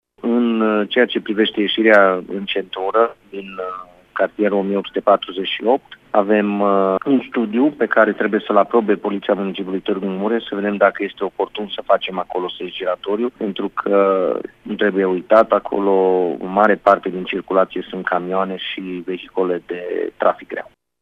Maior a mai spus că Primăria Tg.Mureș a finalizat studiul pentru construirea unui sens giratoriu şi la ieşirea din Cartierul Dâmbu către centura oraşului, unde circulația este îngreunată la orele de vârf: